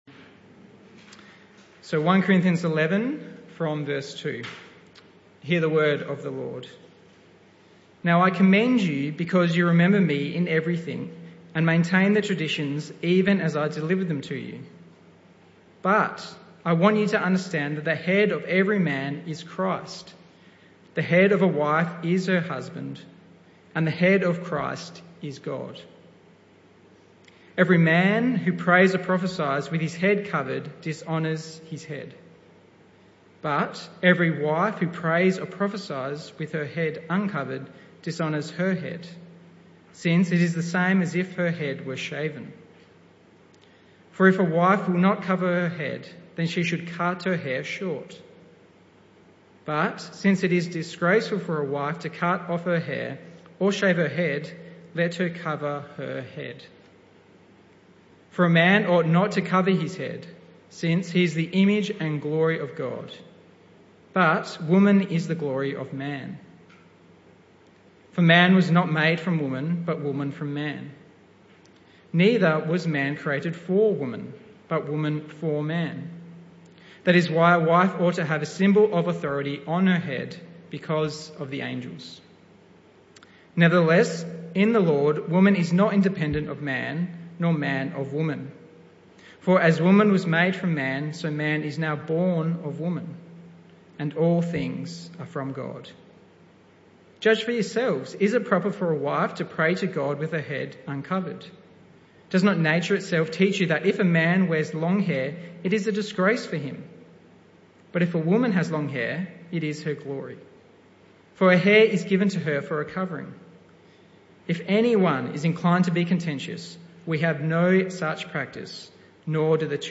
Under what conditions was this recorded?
This talk was part of the PM Service series entitled The Collision of Church & Culture (Talk 7 of 13). Service Type: Evening Service